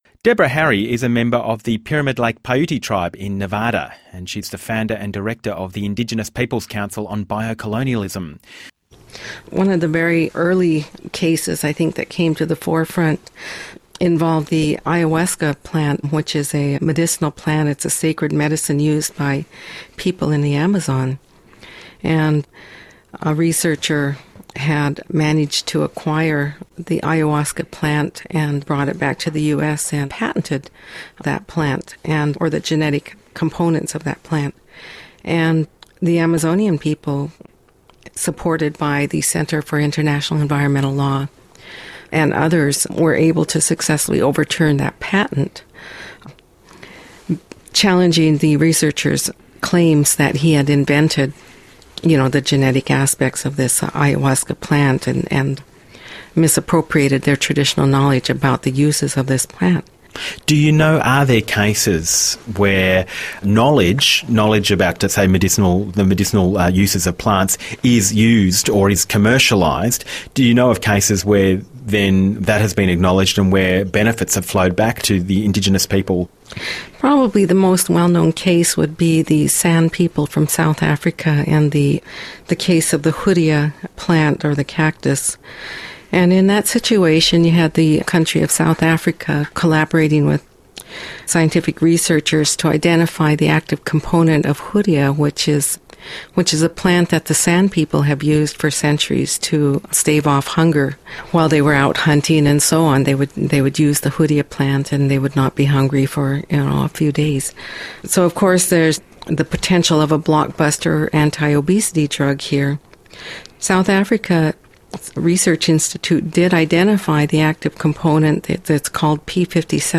You are going to hear an interview broadcast on ABC, an Australian radio station about biopiracy.